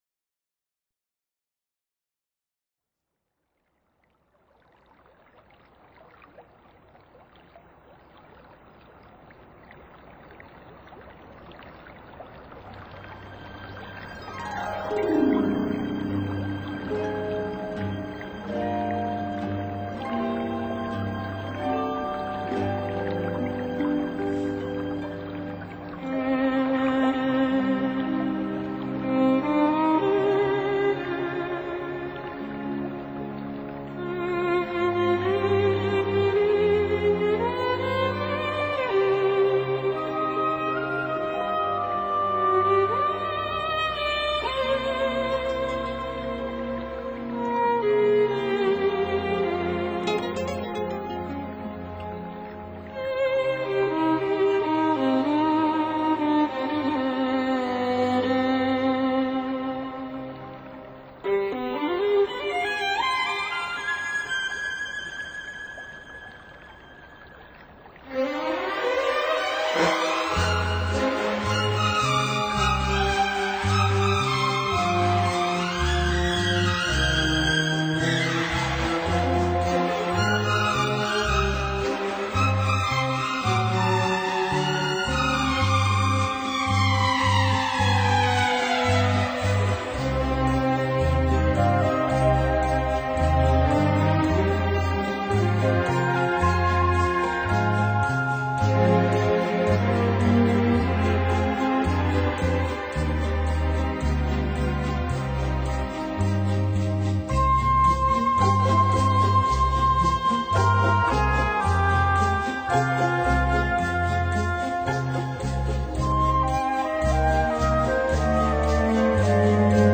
海浪、流水、鸟鸣，风吹过树叶，雨打在屋顶，大自然的原始采样加上改编的著名乐曲合成了天籁之音。